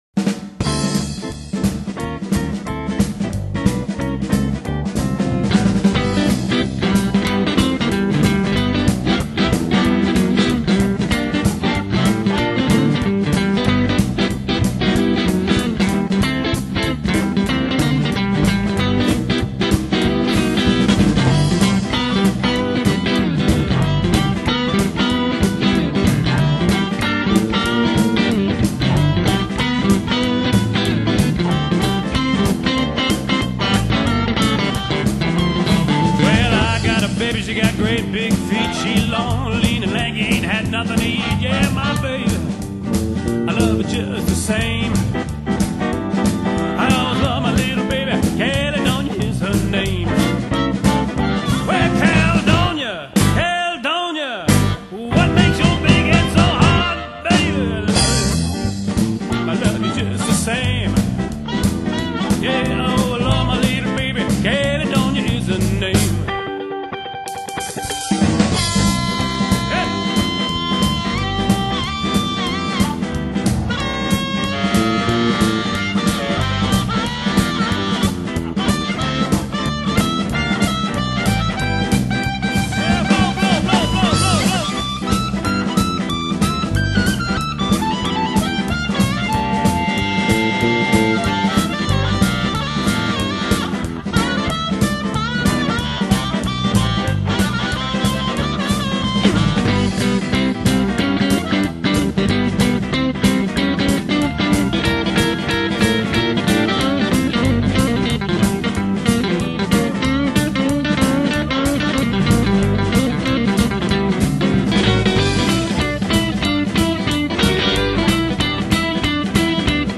Genre: Blues.